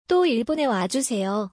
ト イボネ ワジュセヨ